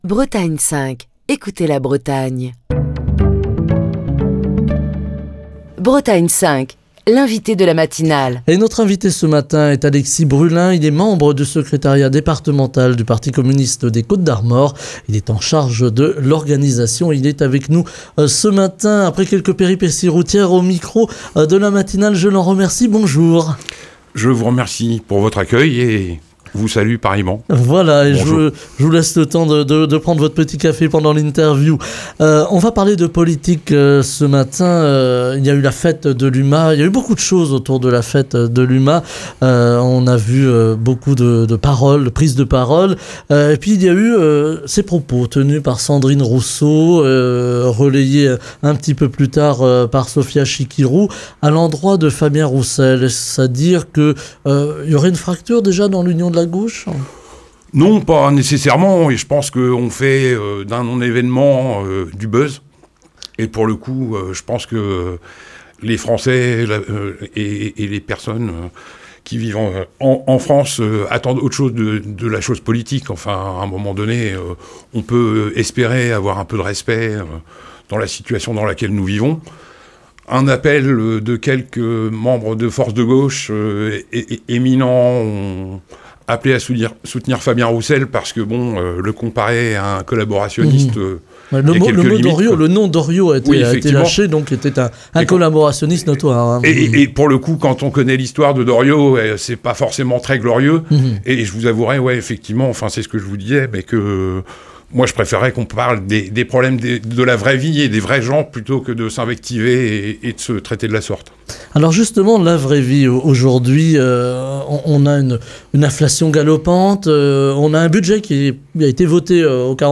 Émission du 29 septembre 2023.